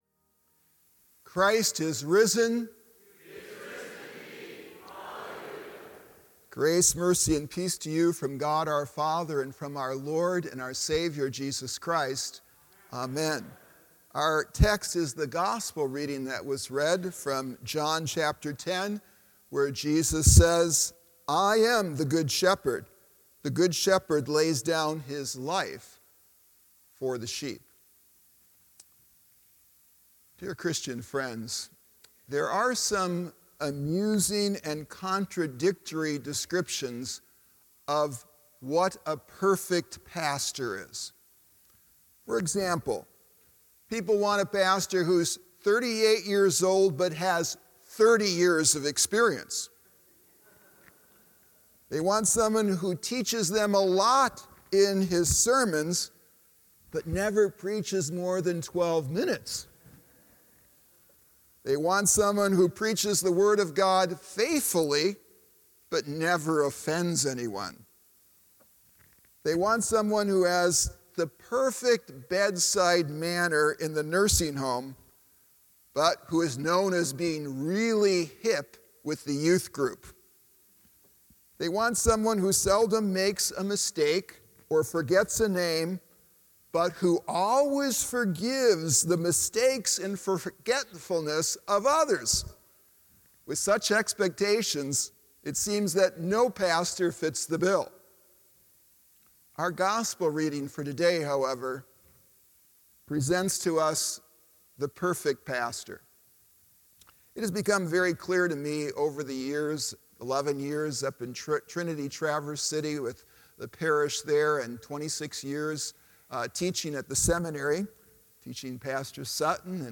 Sermon for Misericordias Domini – Third Sunday of Easter (St. John 10.11-16)